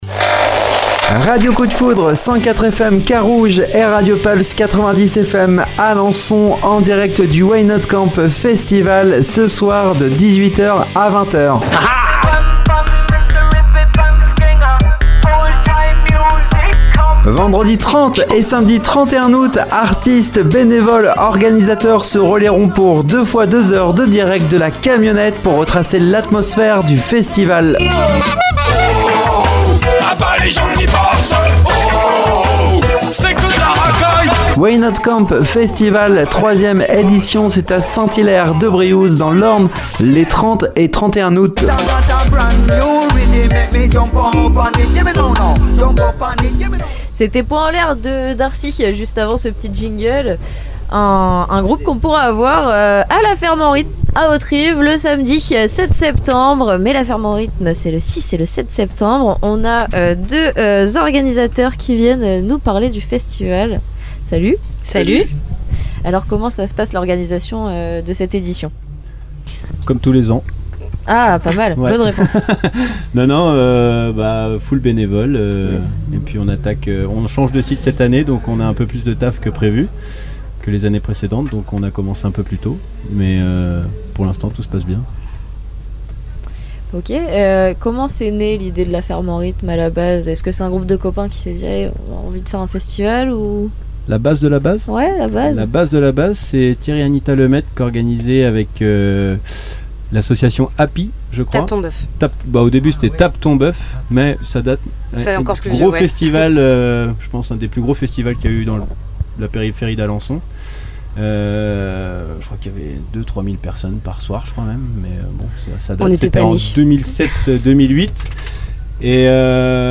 À l’occasion de notre direct au Why Not Camp Festival, nous avons eu le plaisir de discuter avec l’équipe organisatrice de La Ferme en Rythme, un festival dynamique qui aura lieu à Hauterive les 6 et 7 septembre prochains.